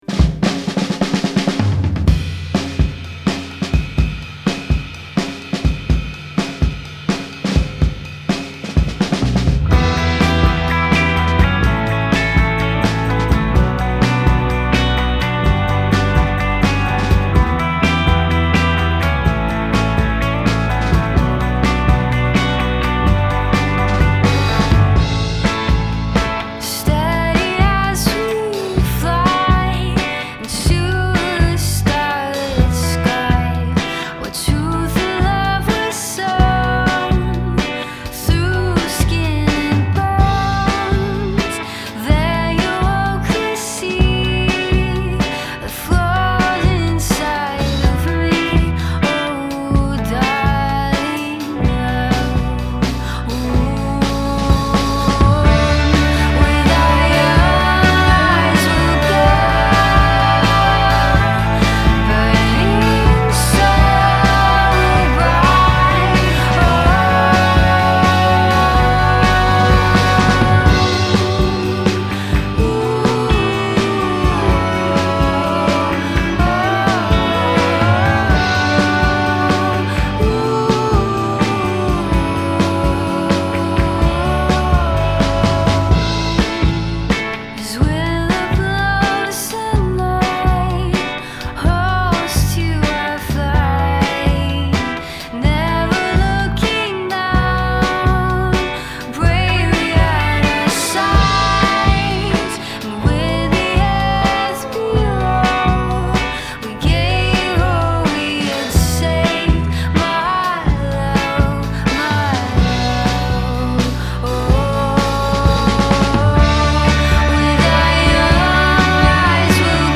lush, disciplined, gorgeous
Lush, disciplined song with a drop-dead gorgeous chorus.
The song is lush, disciplined, unfalteringly interesting.